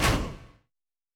Stomp.wav